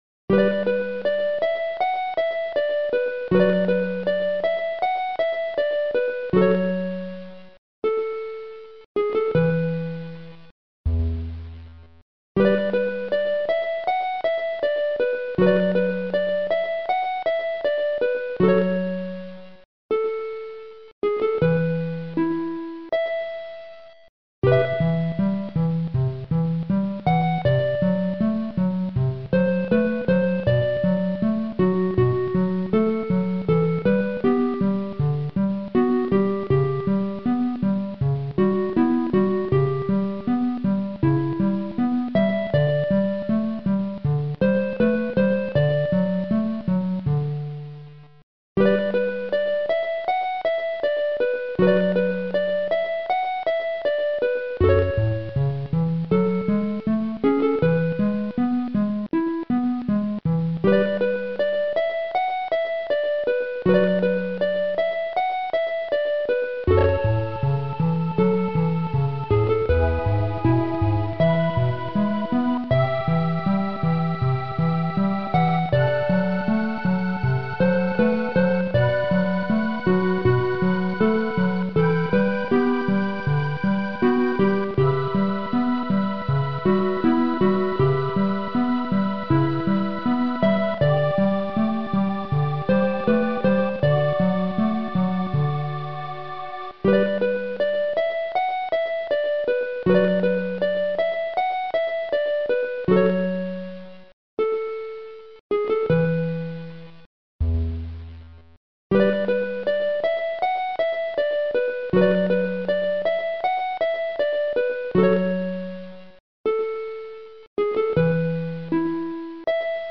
An okay harp piece.